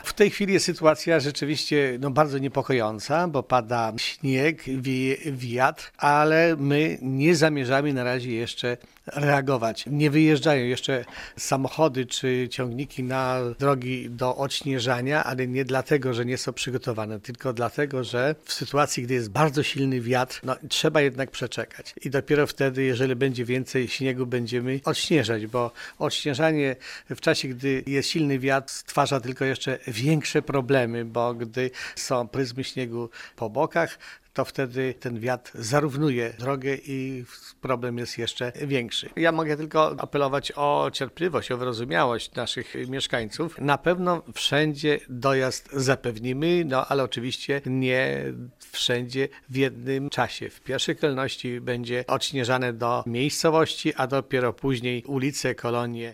W tej sytuacji na efekty pracy drogowców musimy poczekać – tłumaczy zastępca wójta Gminy Łuków Wiktor Osik: